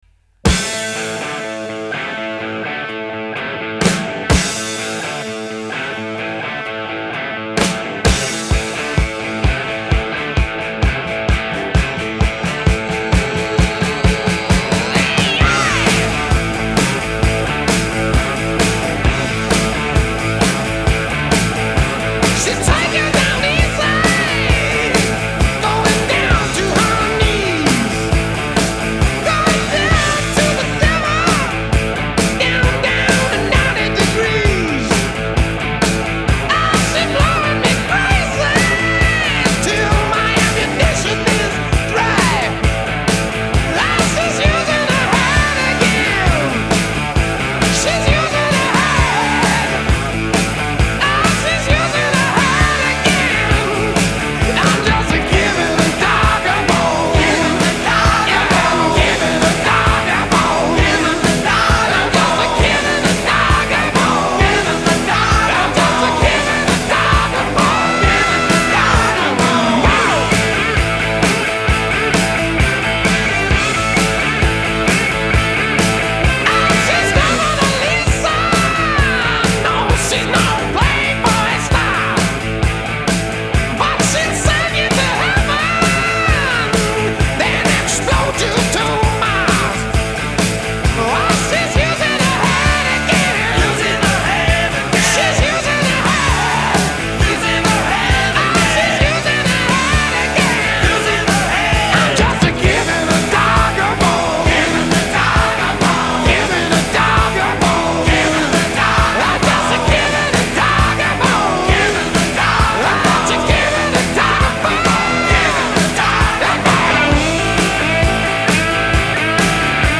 Жанр: Hard Rock, Heavy Metal